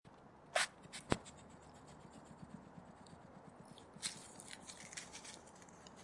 Igel Geräusch in der Paarungszeit: Lautes Röcheln und Fauchen
Das typische Röcheln, Fauchen oder Keckern ist vor allem während der Balz zu hören.
Dabei keckern sie lautstark, um sich gegenseitig einzuschüchtern.
Igel Geräusch
Igel-Geraeusche-Wildtiere-in-Deutschland.mp3